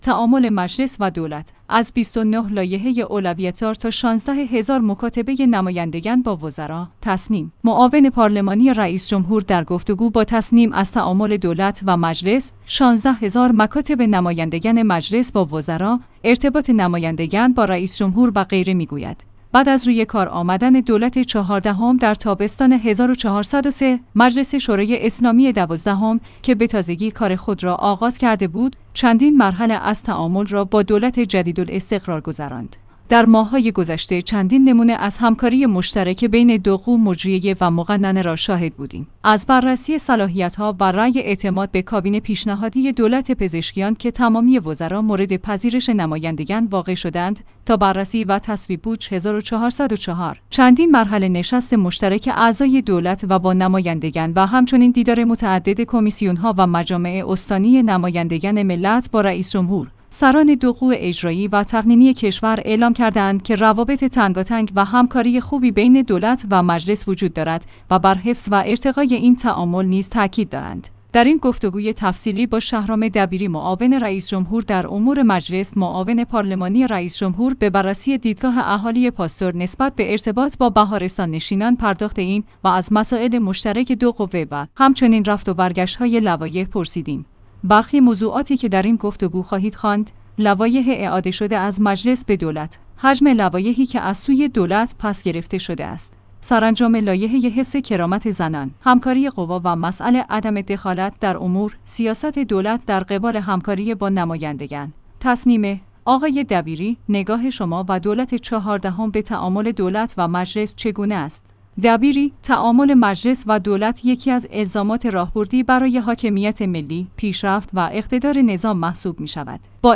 تسنیم/ معاون پارلمانی رئیس‌جمهور در گفت‌وگو با تسنیم از تعامل دولت و مجلس، ۱۶۰۰۰ مکاتبۀ نمایندگان مجلس با وزرا، ارتباط نمایندگان با رئیس‌جمهور و غیره می‌گوید.